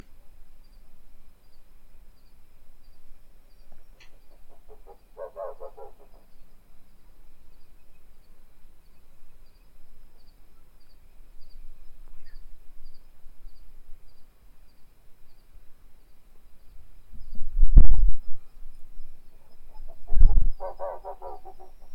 Lechuza Listada (Strix hylophila)
Nombre en inglés: Rusty-barred Owl
Fase de la vida: Adulto
Localidad o área protegida: Parque Provincial Araucaria
Condición: Silvestre
Certeza: Fotografiada, Vocalización Grabada
Lechuza-Listada-Araucaria.mp3